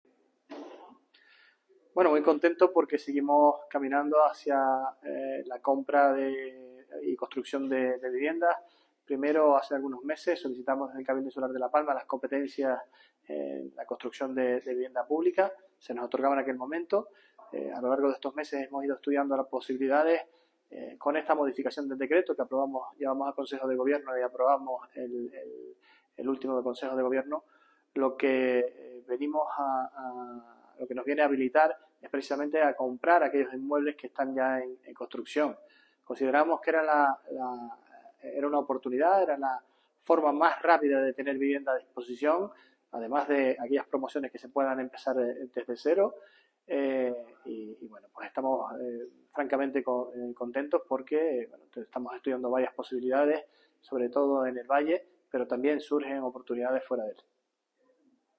Declaraciones Sergio Rodríguez vivienda.mp3